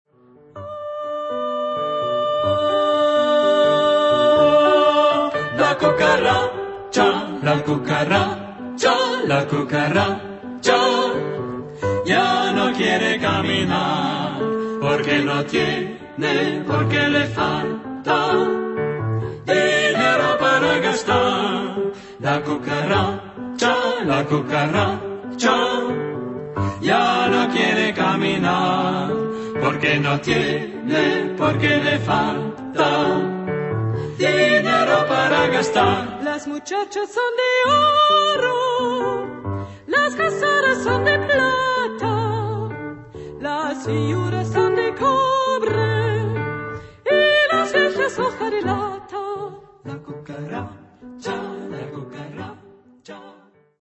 Carácter de la pieza : enérgico ; alegre ; rítmico
Tipo de formación coral: SATB  (4 voces Coro mixto )
Instrumentación: Piano  (1 partes instrumentales)
Tonalidad : sol mayor